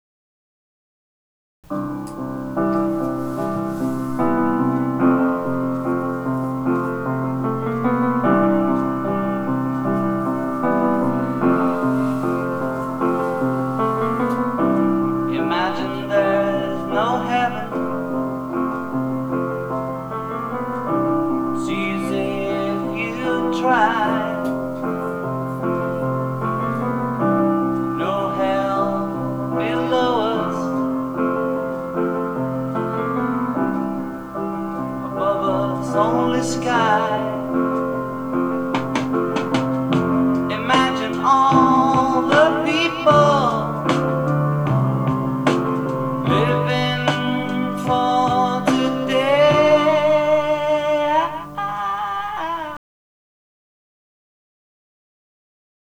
同じ音源を２つのスピーカーから出して録音、比較するのです。
TASCAMのレコーダー、DR-07MK2を小さな三脚にセットしました。
出だしのピアノや、後半のドラムスは、明らかに音の膨らみが違います。